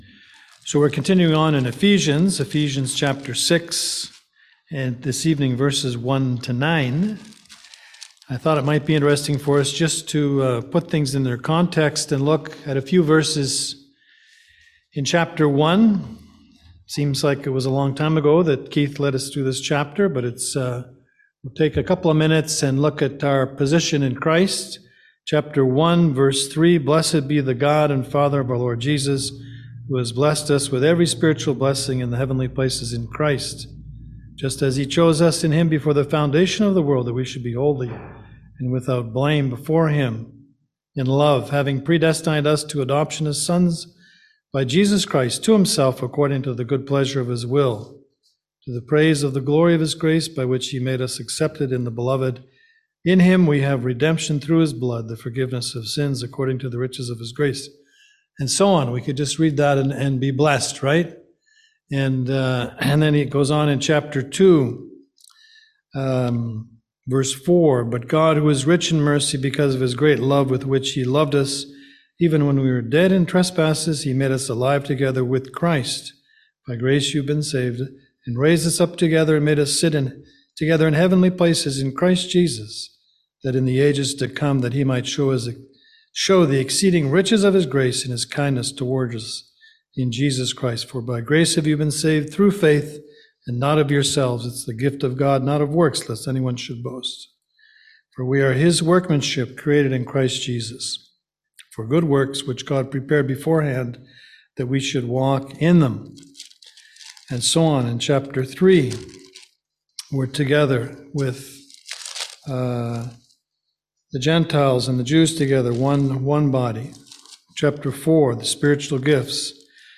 Series: Ephesians 2022 Passage: Ephesians 6:1-9 Service Type: Seminar